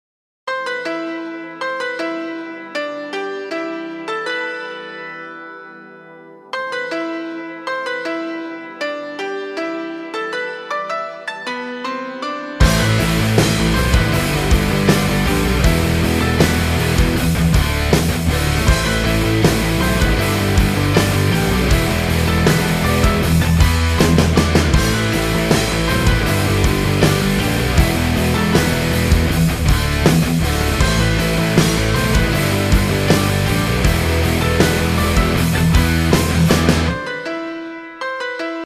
без слов
пианино
power metal